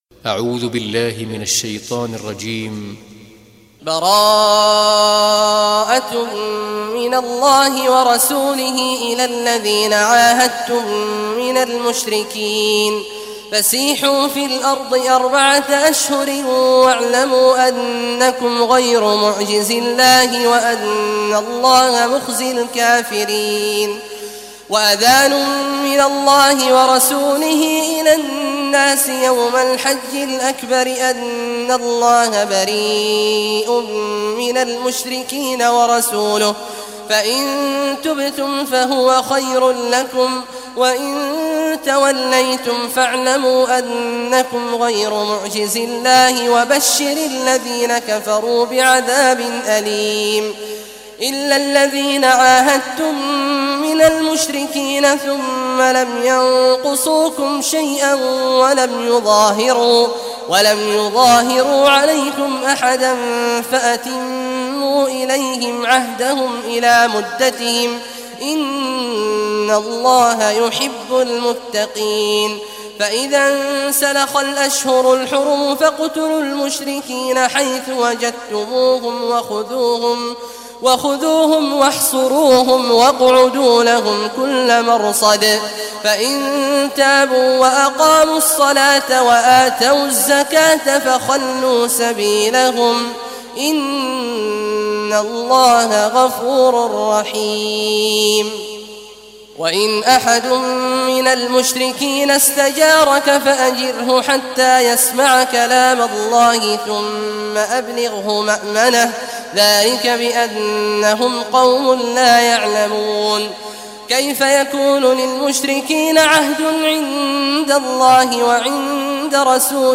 Surah Tawbah Recitation by Sheikh Awad al Juhany
Surah At-Tawbah, listen or play online mp3 tilawat / recitation in Arabic in the beautiful voice of Sheikh Abdullah Awad al Juhany.
9-surah-taubah.mp3